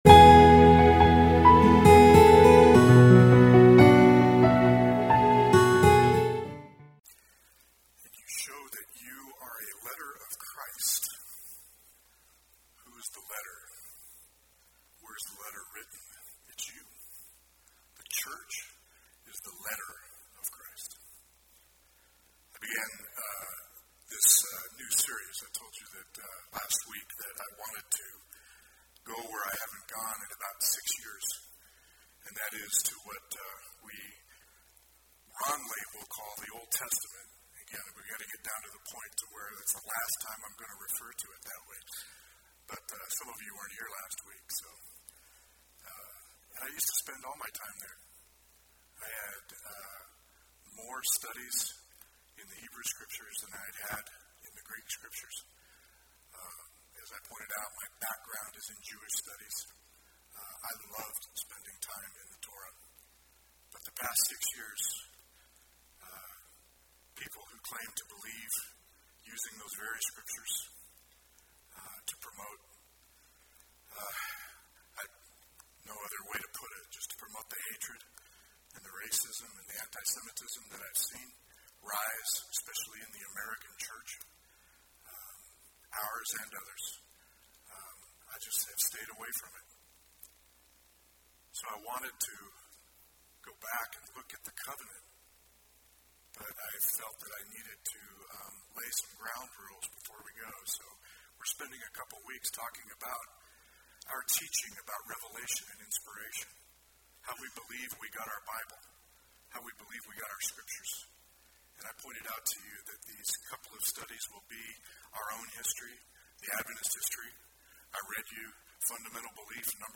Home › Sermons › “Speak Lord!